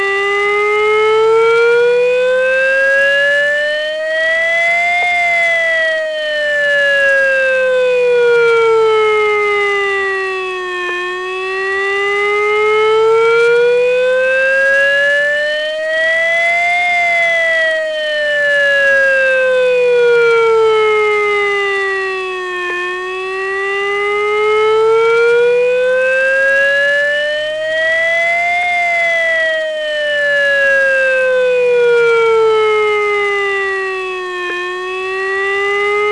Com sonen les sirenes de risc químic (2025)
Aquest registre conté els sons emesos per les sirenes d’avís a la població en situacions de risc químic. El senyal d’inici d’alerta consisteix en tres sons d’un minut de durada, separats per silencis de cinc segons.
so sirenes inici alerta risc químic.mp3